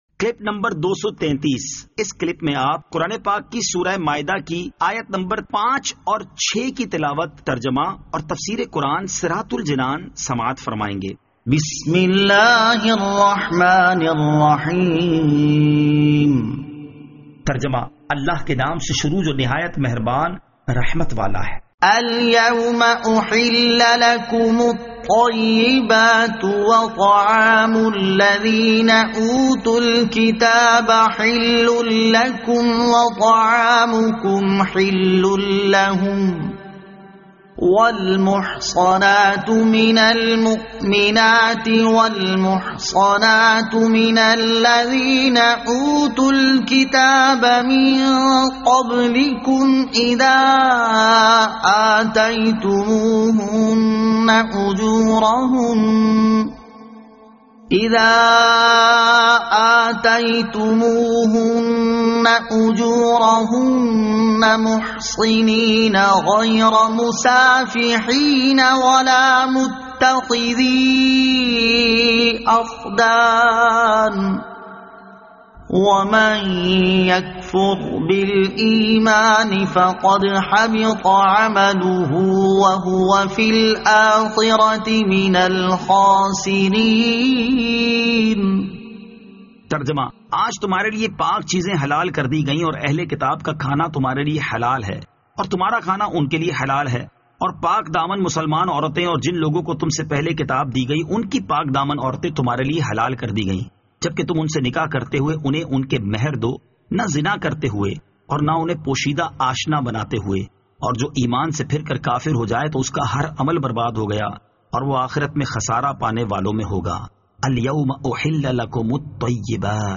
Surah Al-Maidah Ayat 05 To 06 Tilawat , Tarjama , Tafseer